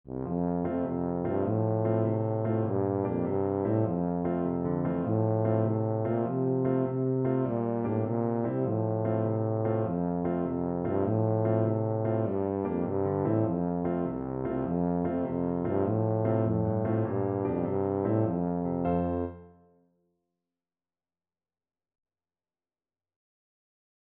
Tuba
F major (Sounding Pitch) (View more F major Music for Tuba )
Quick two in a bar . = c.100
6/8 (View more 6/8 Music)
Traditional (View more Traditional Tuba Music)